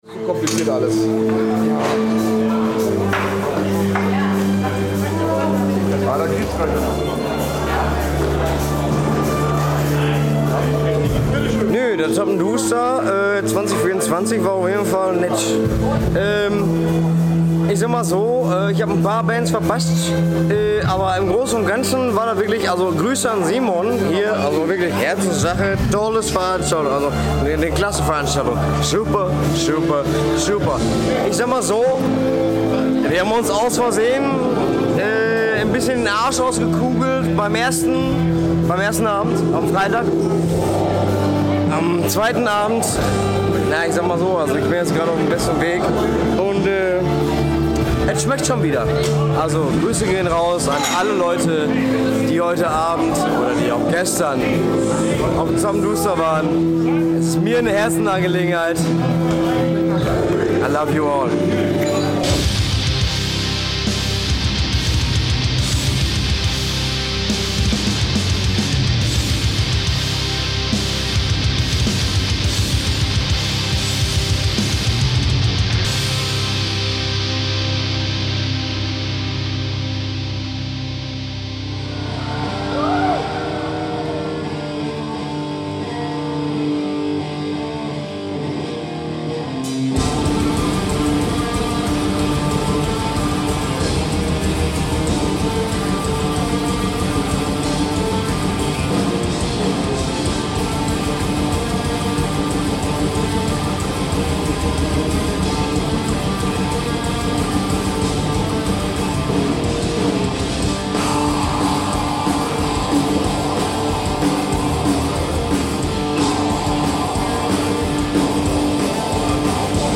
Interview
Liveausschnitte von den Auftritten von Agrypnie und Uada.